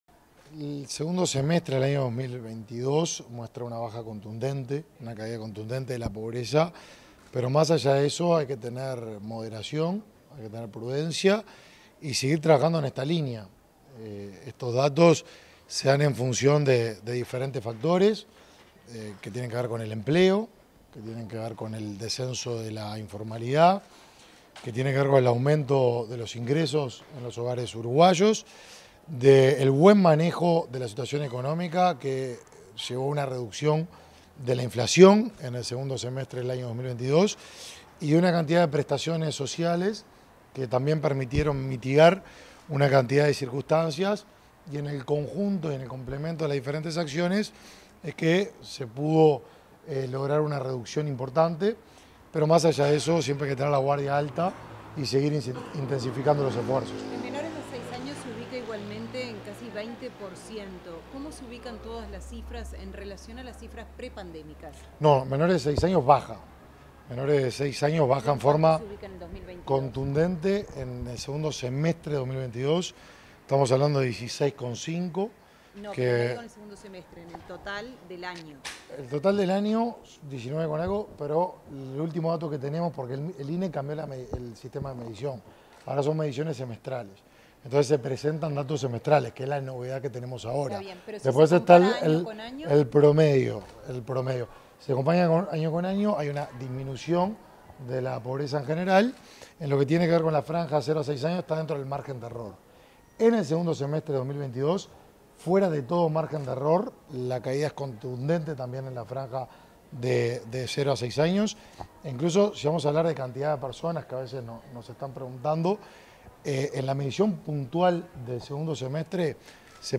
Declaraciones del ministro de Desarrollo Social, Martín Lema